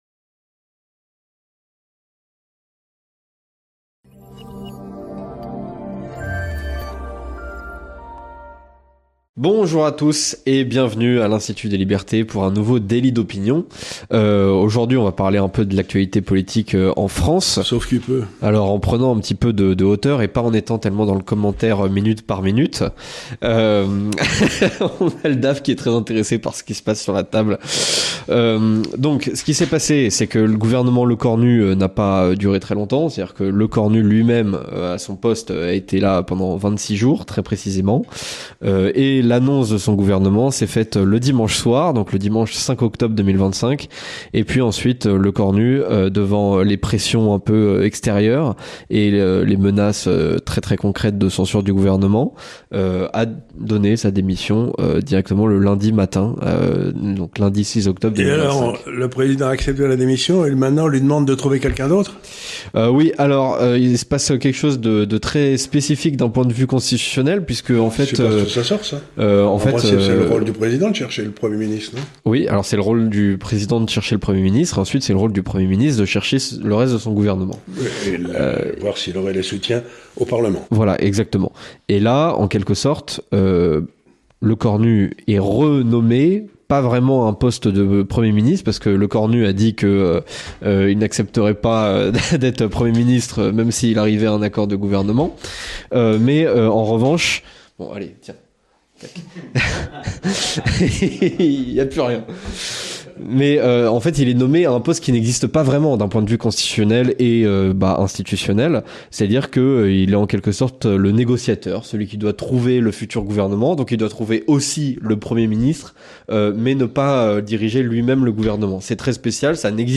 Dans l'entretien de cette semaine